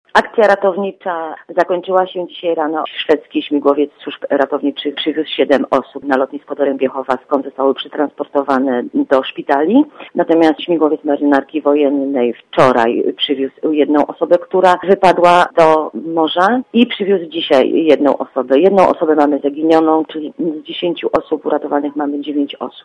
Dla Radia ZET mówi